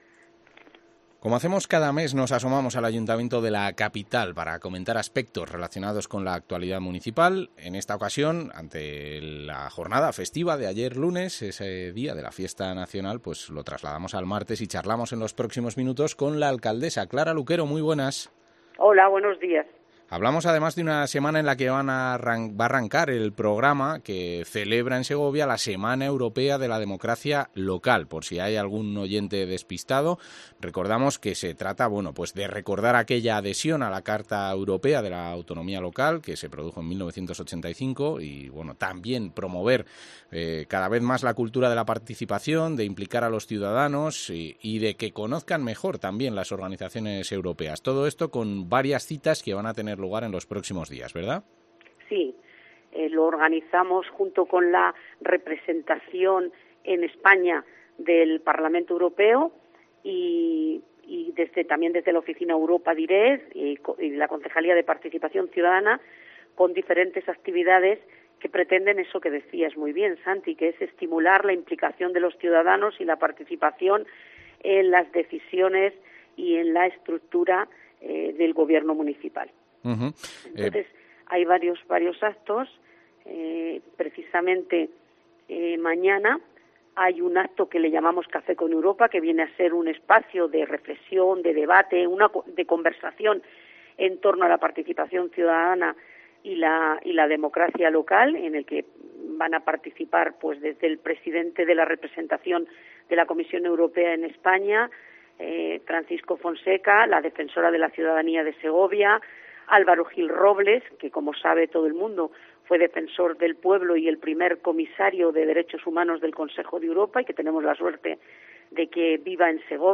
Entrevista a la alcaldesa de Segovia, Clara Luquero